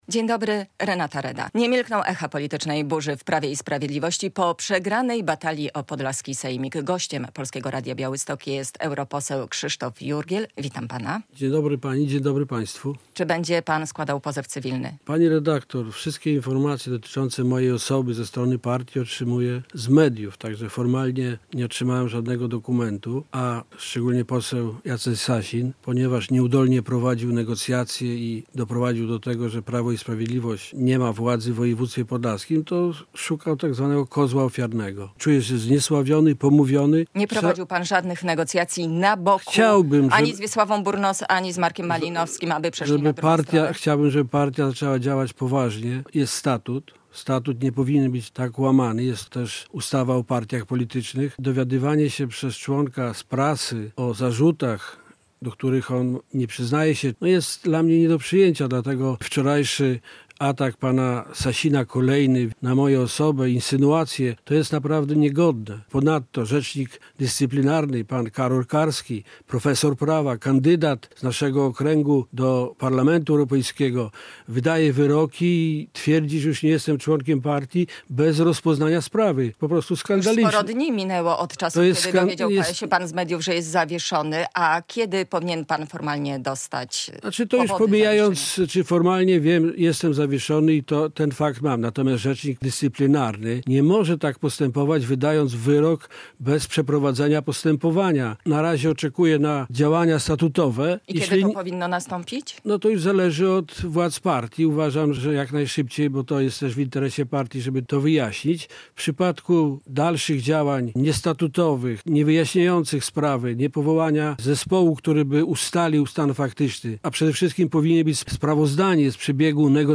europoseł PiS